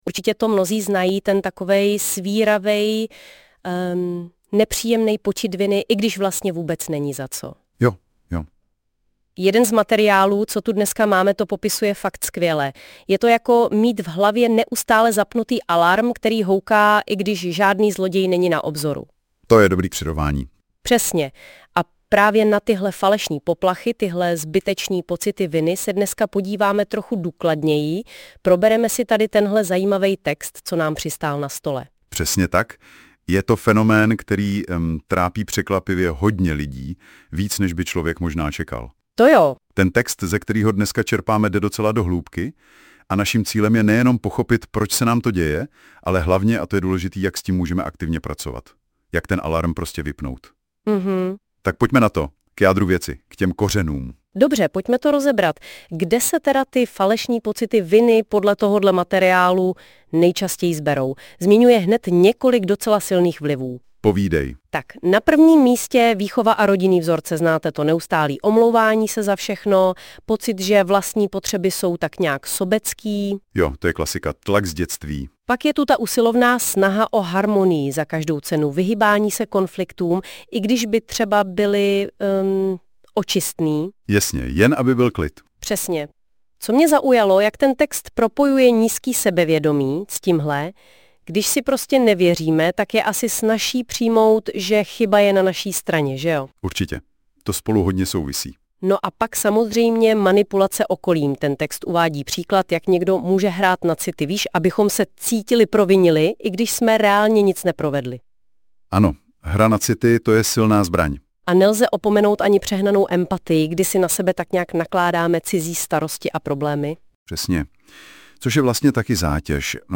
Audioverze vytvořená na základě tohoto článku pomocí nástroje NotebookLM.